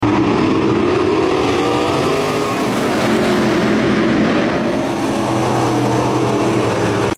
Dirt Car ASMR🎧😩 Sound Effects Free Download